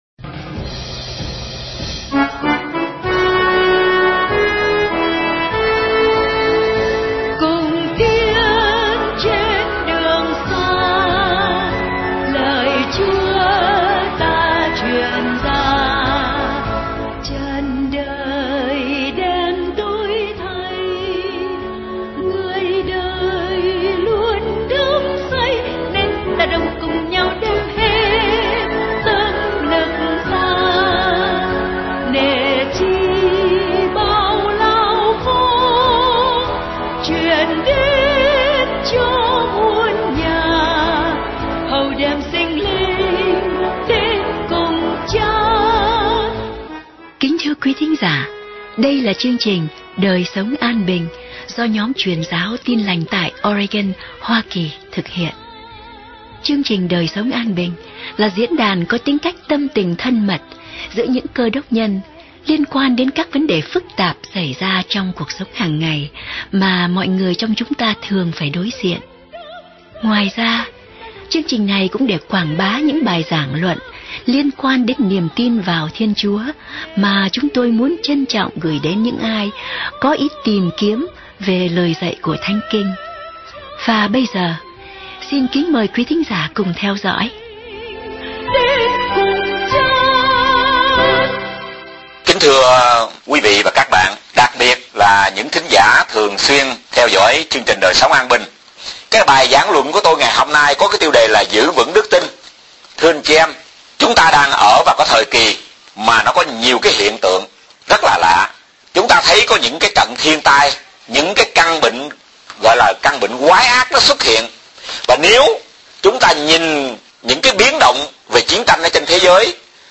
Bài giảng luận 27 phút Đề tài